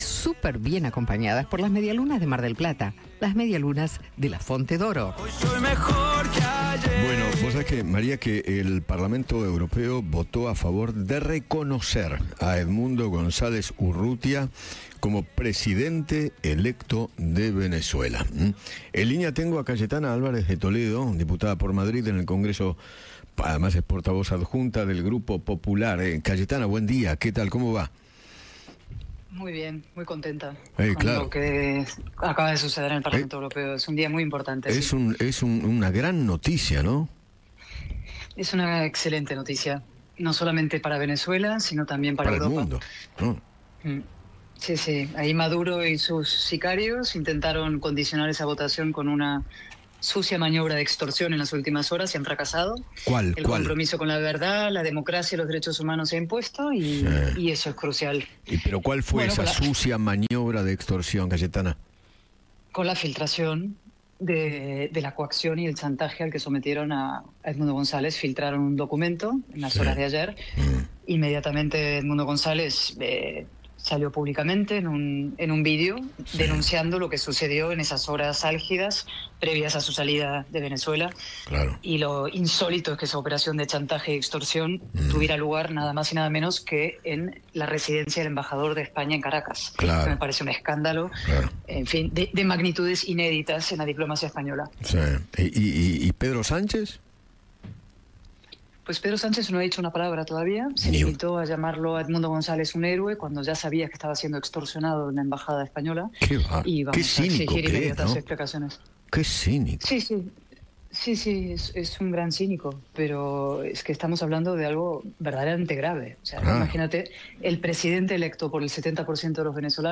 Eduardo Feinmann habló con la diputada española, Cayetana Álvarez De Toledo, sobre la resolución que aprobó el Parlamento Europeo con 309 votos a favor, 201 en contra y 12 abstenciones.